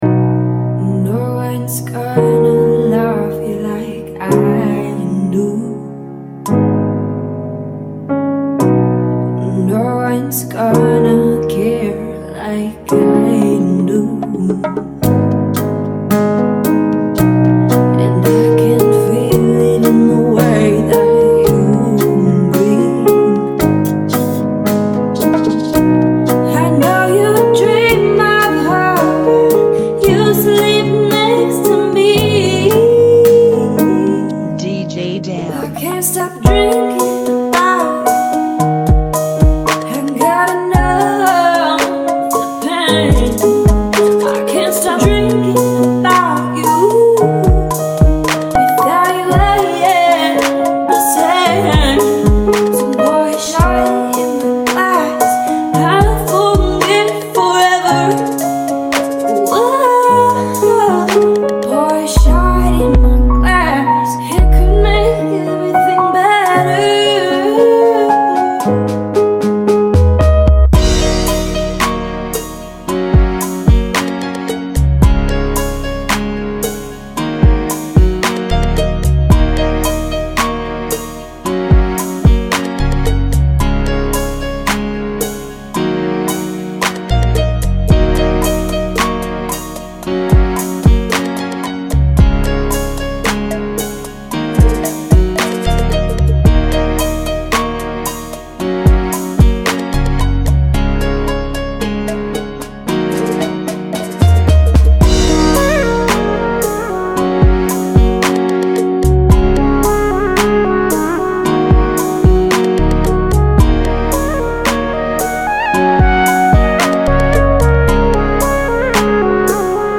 (112 BPM)
Genre: Bachata Remix